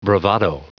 Prononciation du mot bravado en anglais (fichier audio)
Prononciation du mot : bravado